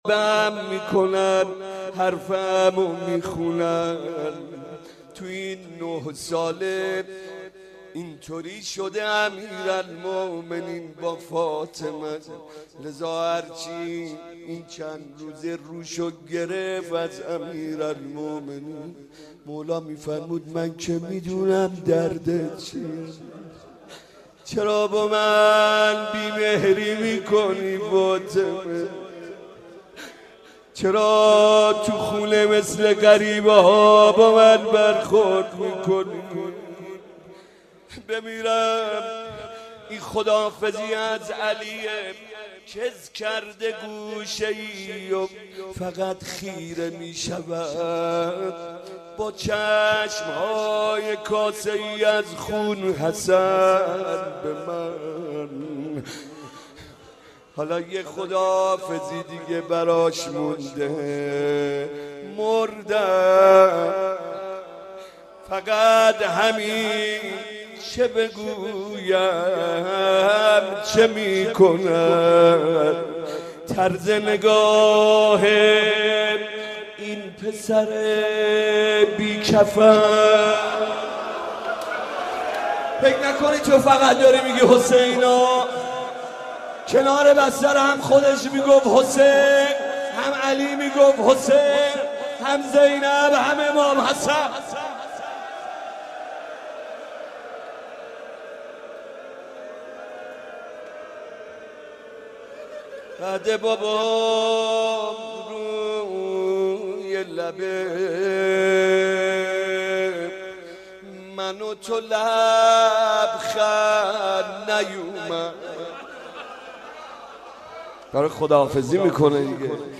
دانلود مداحی نفس های آخرم - دانلود ریمیکس و آهنگ جدید
روضه خوانی، شهادت حضرت فاطمه زهرا(س)